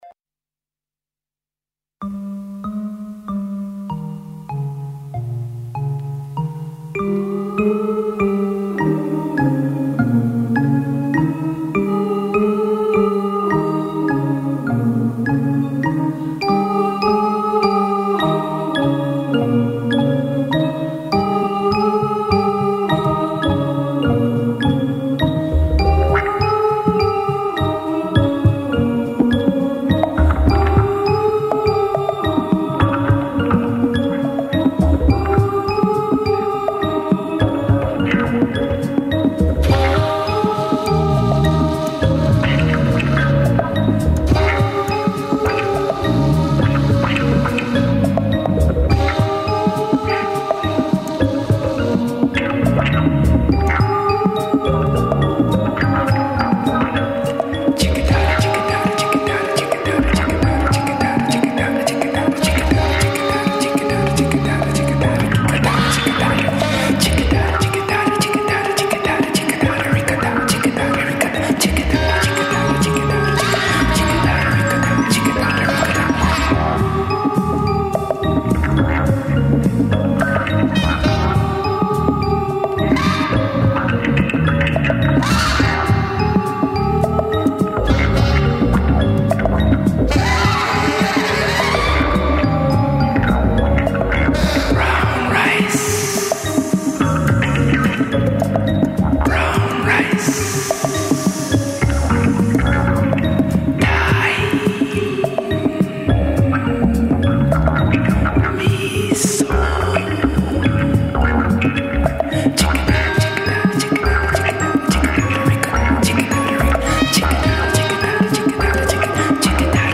Il sabato del villaggio... una trasmissione totalmente improvvisata ed emozionale. Musica a 360°, viva, legata e slegata dagli accadimenti.